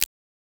Abstract Click (4).wav